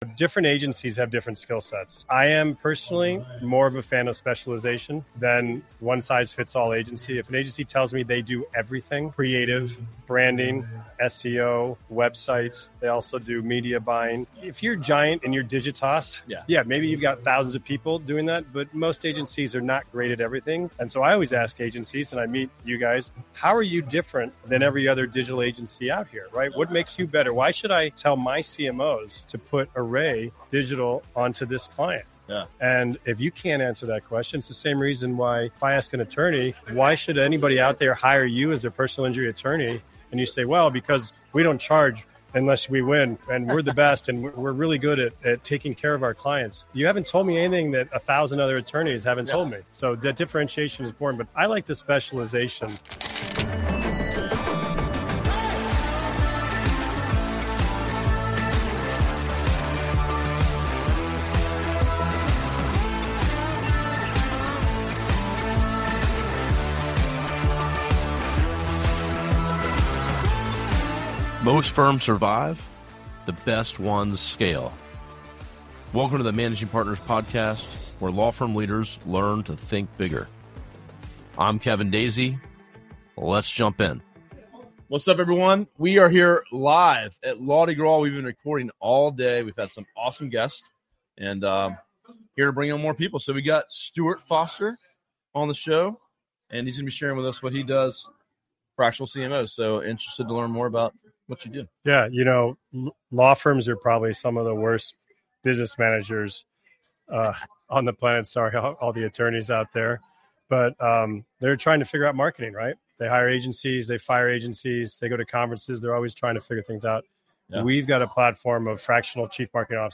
Live from Law-Di-Gras
interviews